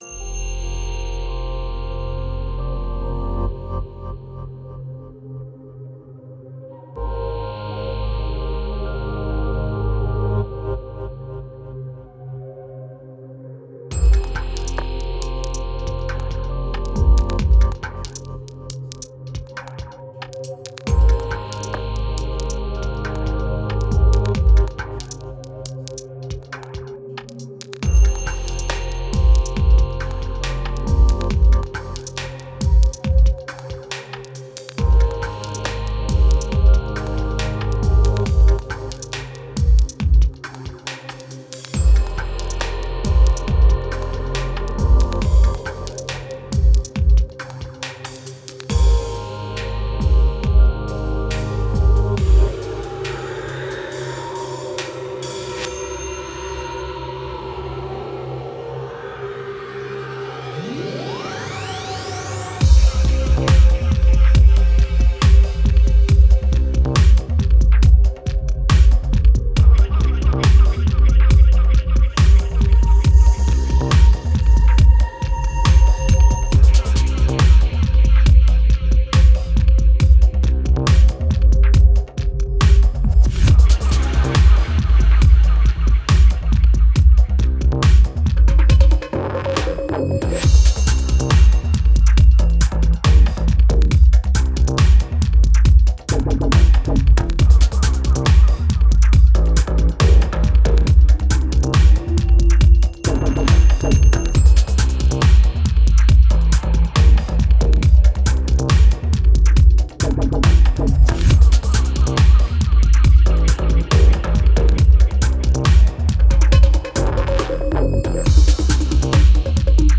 Genre: Downtempo, Dub.